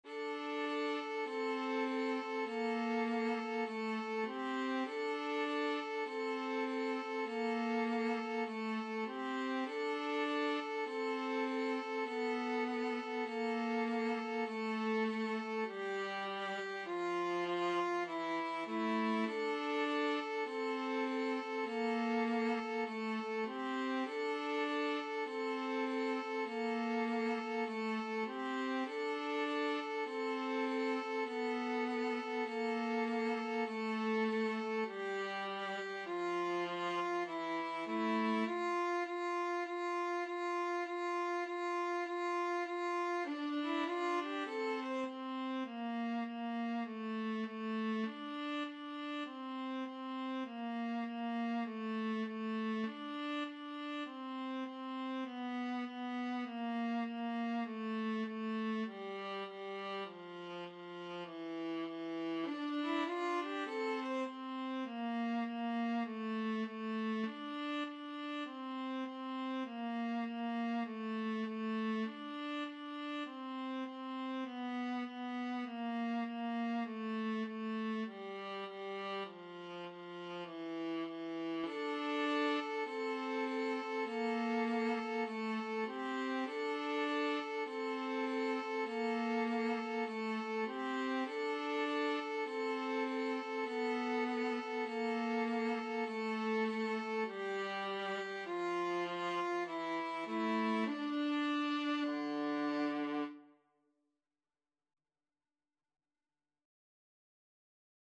4/4 (View more 4/4 Music)
Jazz (View more Jazz Violin-Viola Duet Music)